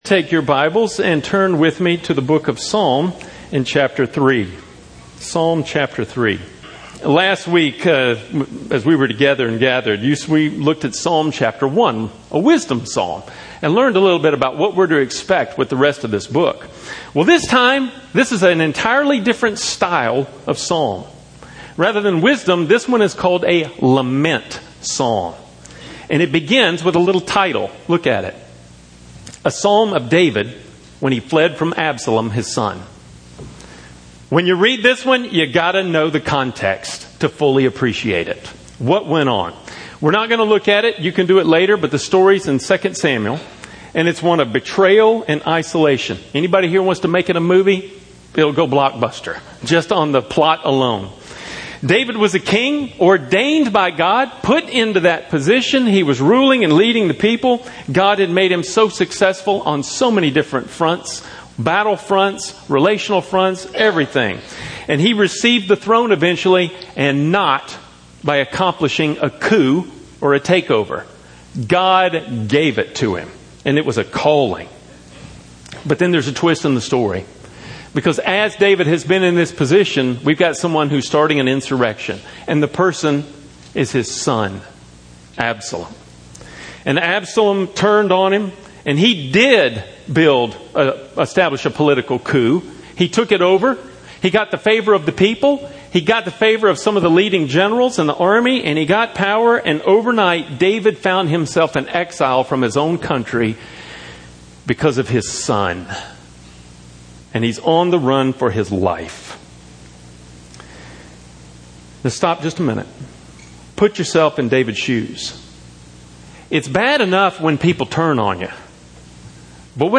2017 Sermon